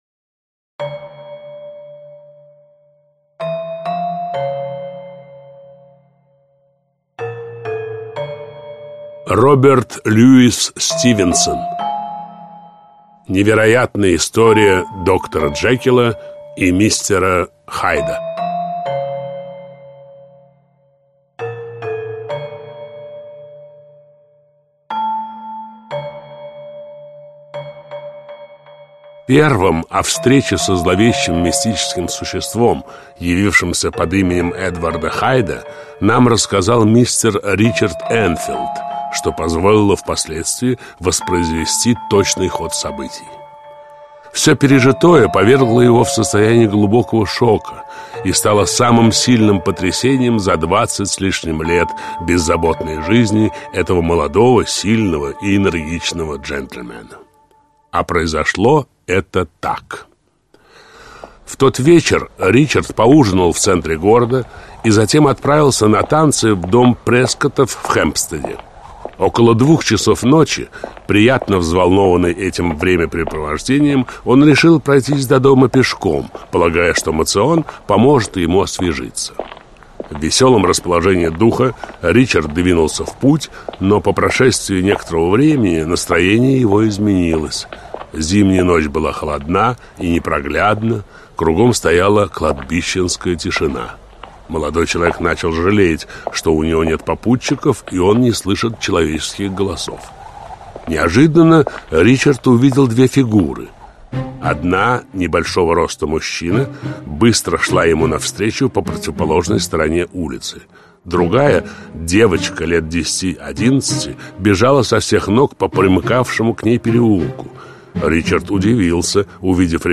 Аудиокнига Невероятная история доктора Джекила и мистера Хайда (спектакль) | Библиотека аудиокниг
Aудиокнига Невероятная история доктора Джекила и мистера Хайда (спектакль) Автор Роберт Льюис Стивенсон Читает аудиокнигу Лев Дуров.